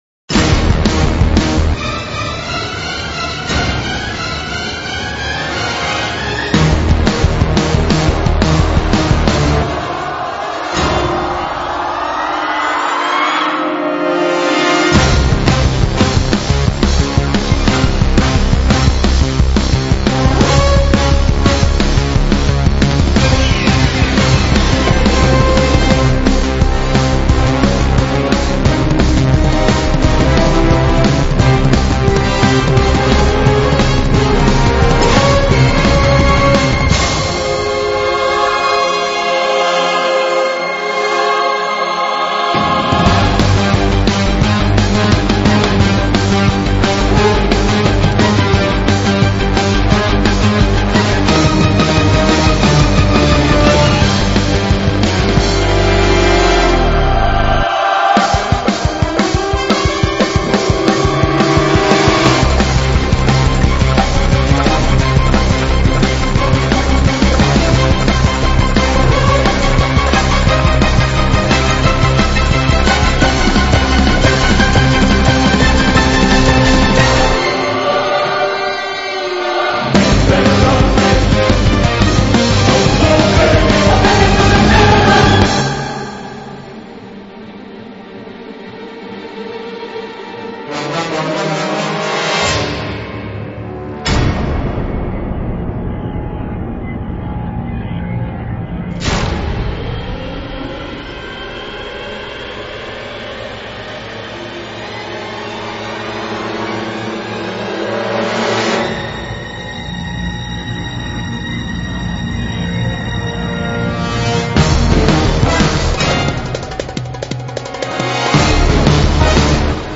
la composition explosive